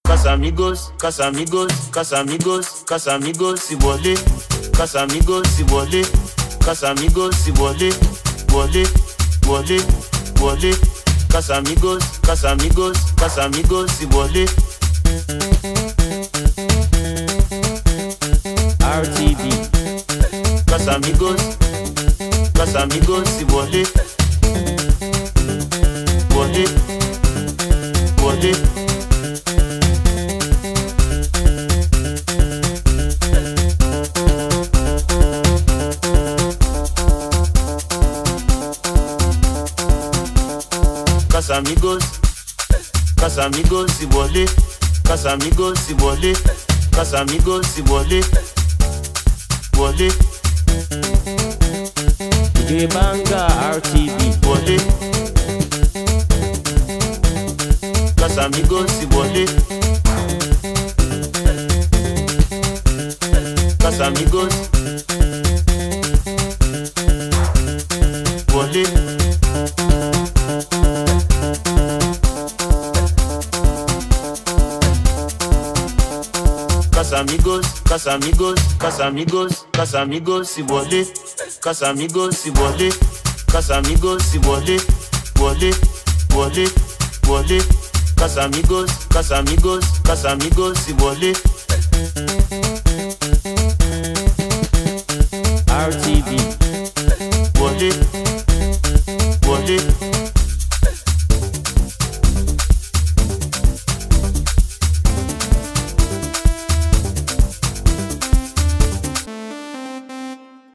debuts another mind-blowing beat